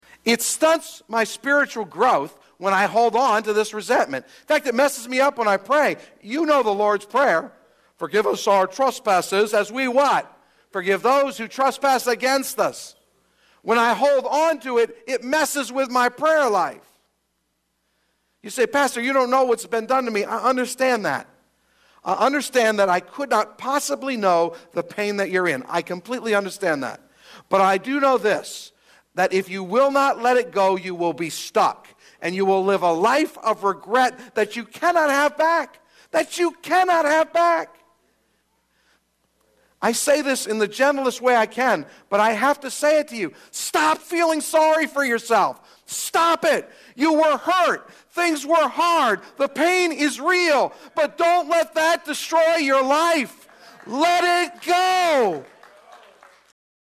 one-minute-sermon-let-it-go-clip1.mp3